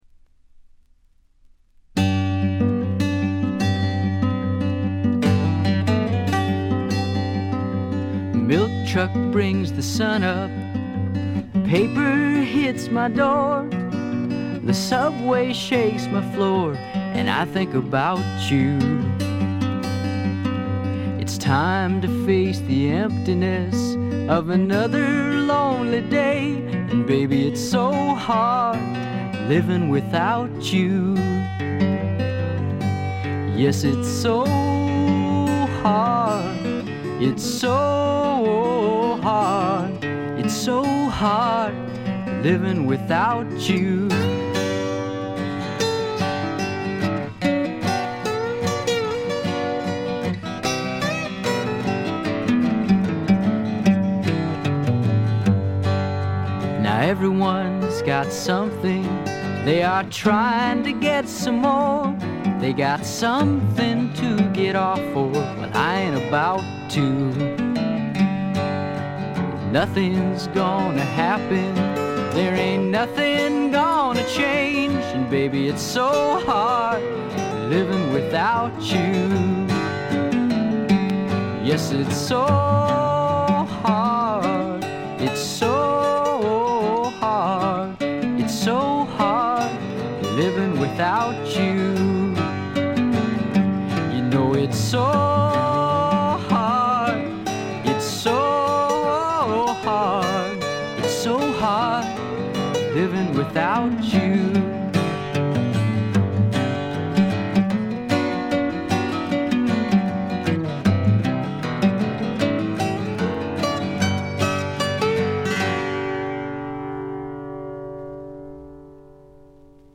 部分試聴ですが、チリプチ少々。
試聴曲は現品からの取り込み音源です。